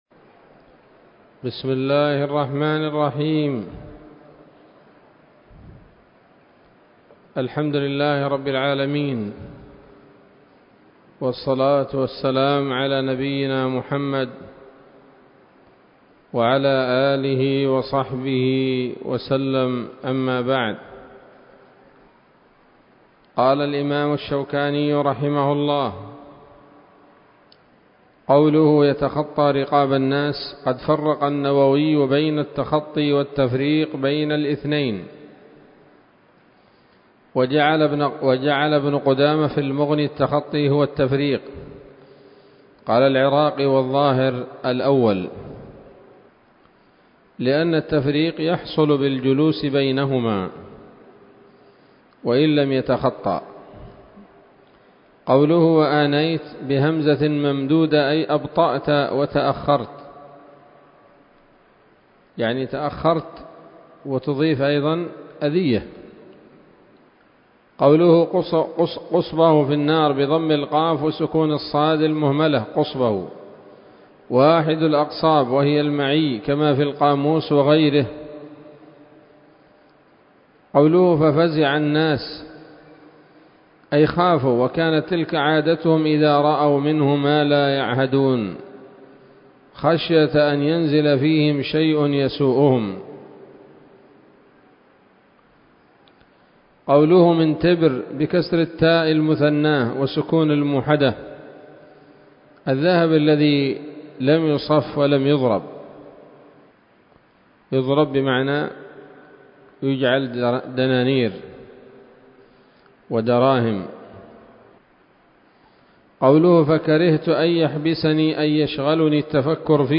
الدرس التاسع عشر من ‌‌‌‌أَبْوَاب الجمعة من نيل الأوطار